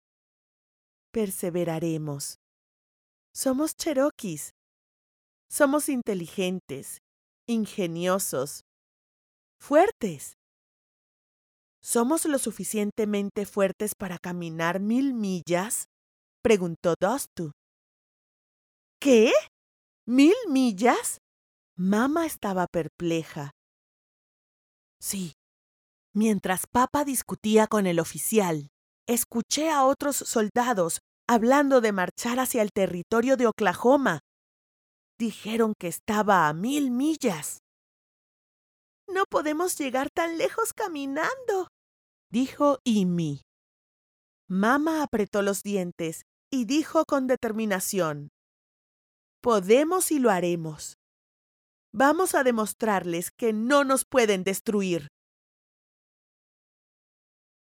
Female
Character, Confident, Conversational, Corporate, Friendly, Natural, Young
Microphone: Audio-Technica AT4030a Cardioid Condenser Microphone